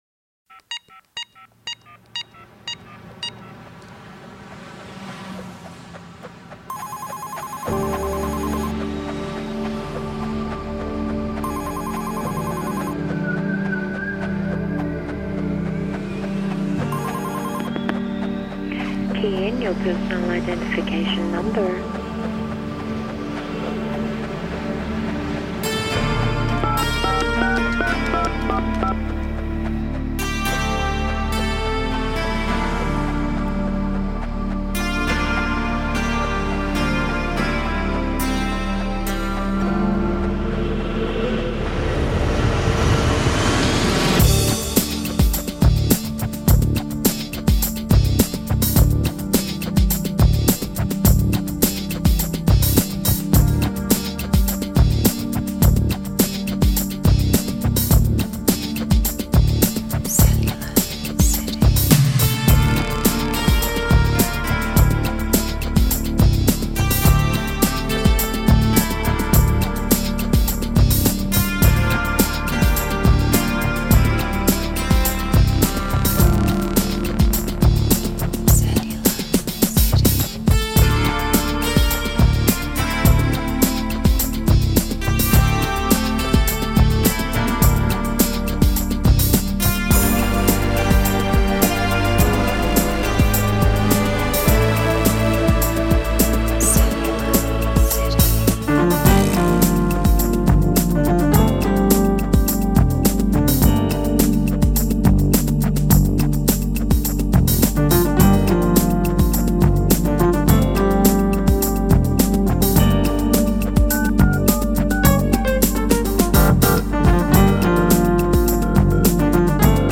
Take 2选择了更多的合成音乐
Newage